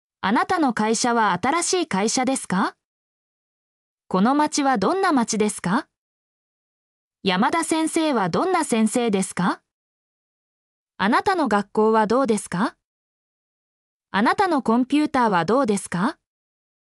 mp3-output-ttsfreedotcom-59_CUv7I8Rs.mp3